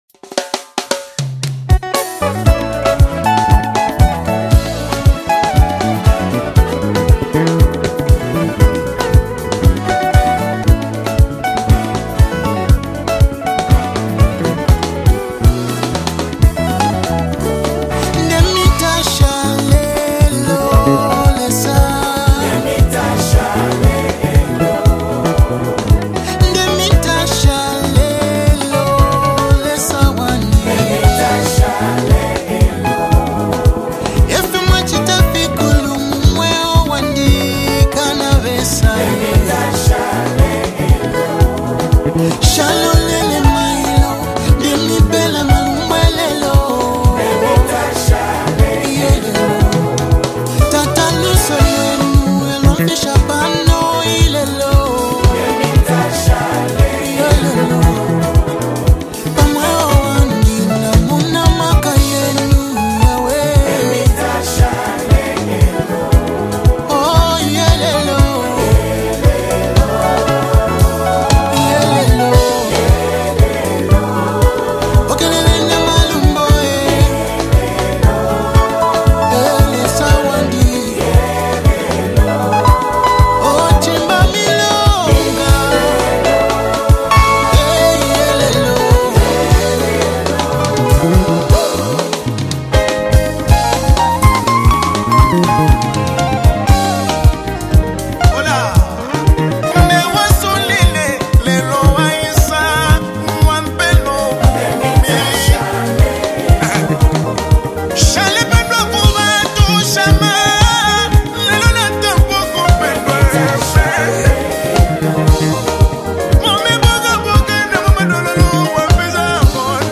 Latest Worship Song 2025
soul-stirring worship song